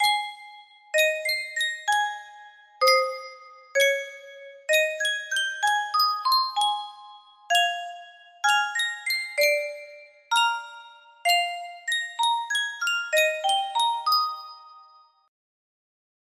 Yunsheng Music Box - Sweet Rosie O'Grady 5969 music box melody
Full range 60